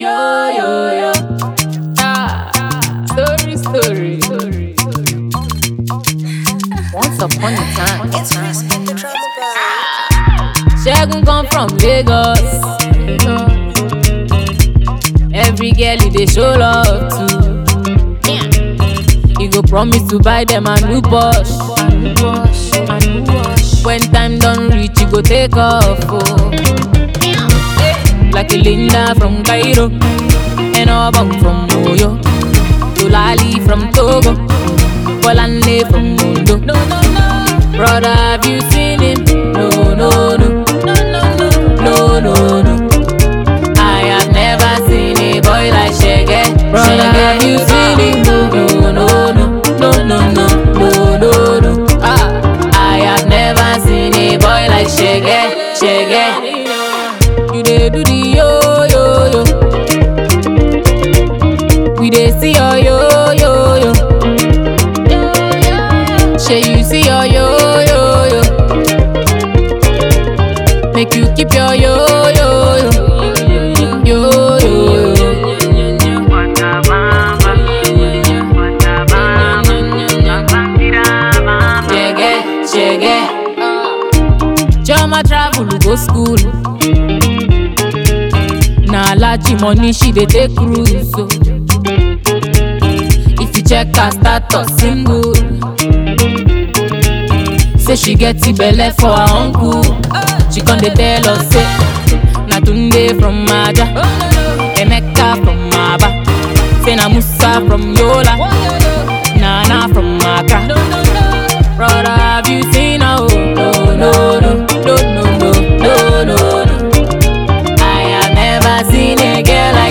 Nigerian Afropop singer and songwriter